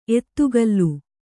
♪ ettugallu